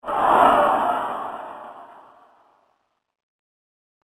جلوه های صوتی
دانلود صدای باد 61 از ساعد نیوز با لینک مستقیم و کیفیت بالا